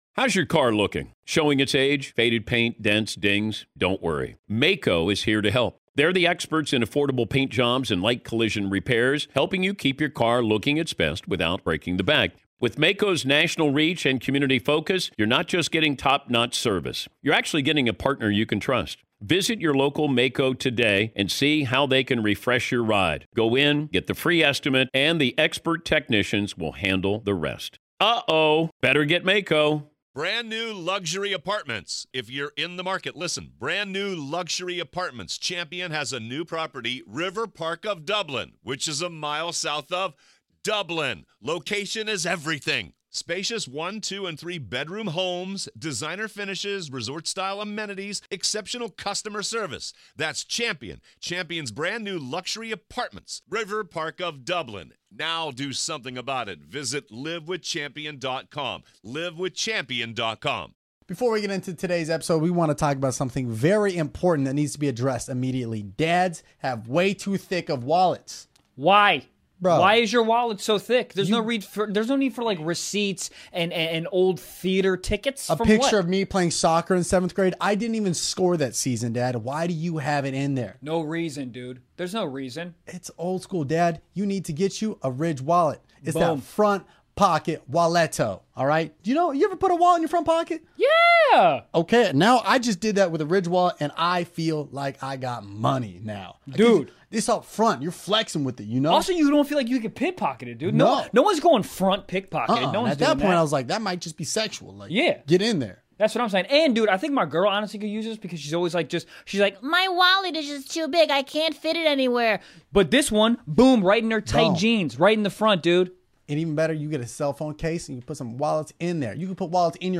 Viral SUPERSTAR comedian Kevonstage sits down with Stiff Socks.